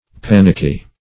panicky \pan"ick*y\ adj.